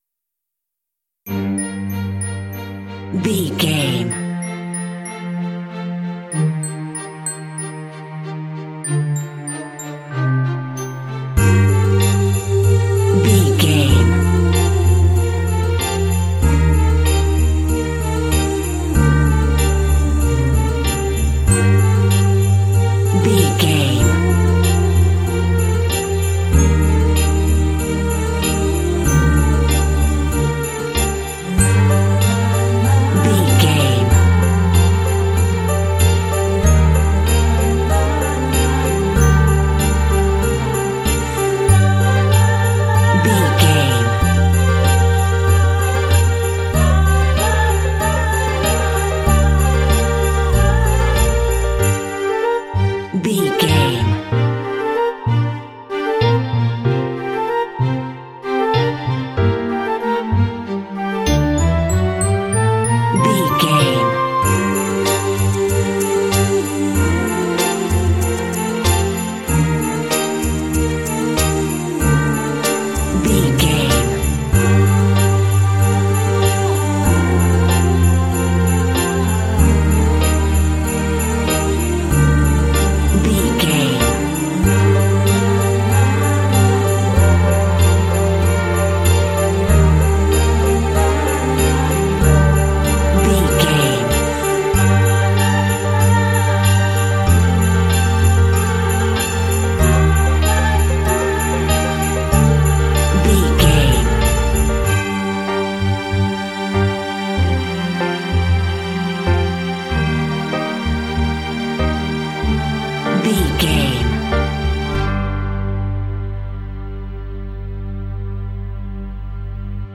has soulful vocals and electric guitar offbeat shots.
Aeolian/Minor
festive
tension
groovy
soothing
orchestra
strings
vocals
electric guitar
contemporary underscore
cinematic
hip hop